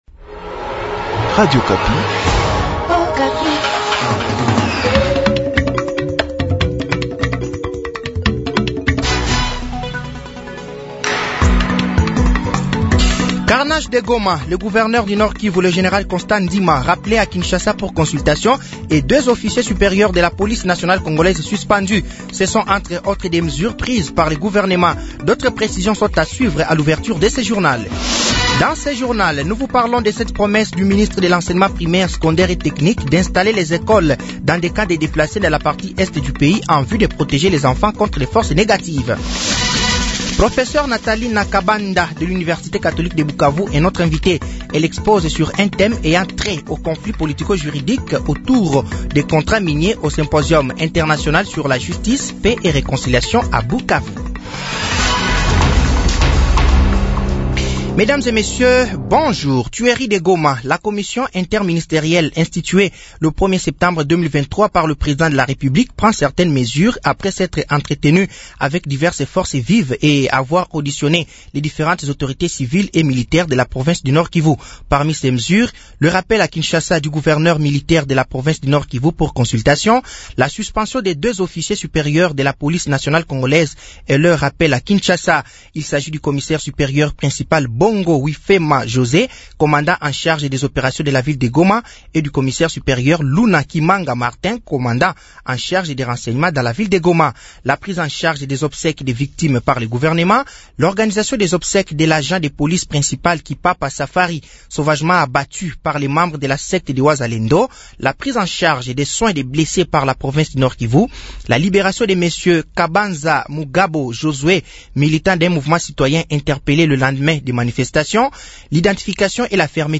Journal français de 12h de ce mardi 05 septembre 2023